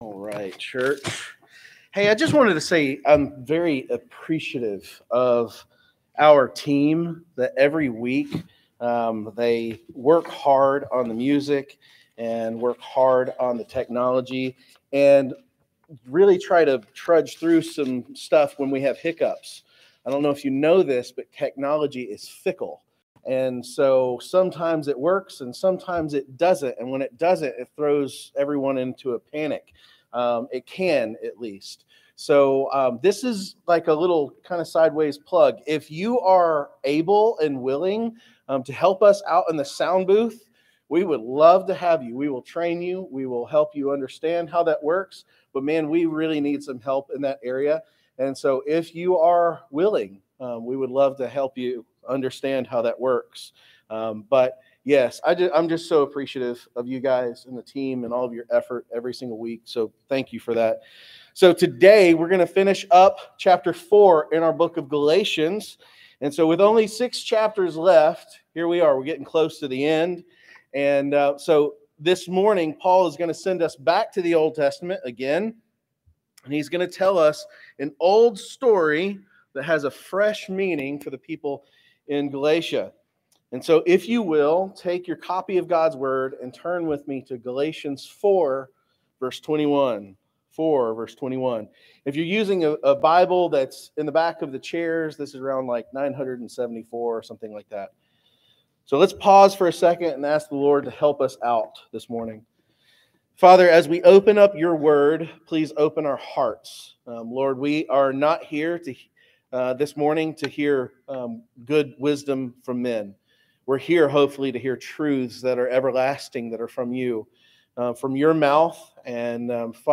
Sermons | Waleska First Baptist Church
Guest Speaker